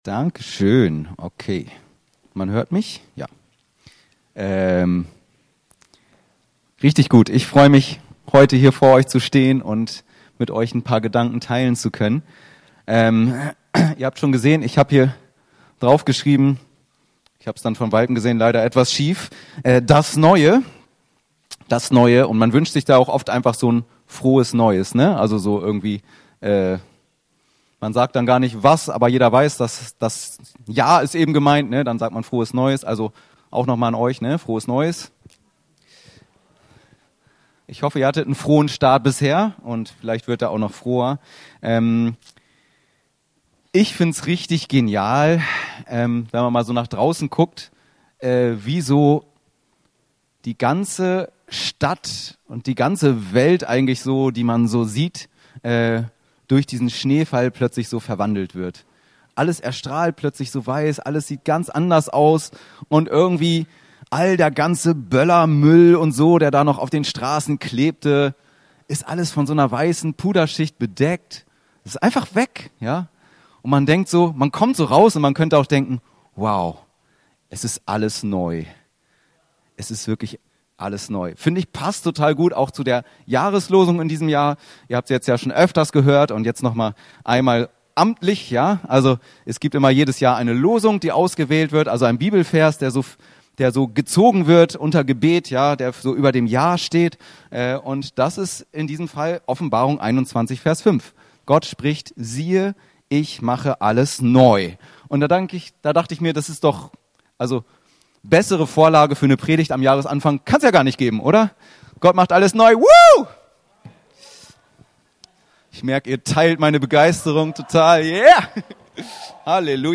Predigten Podcast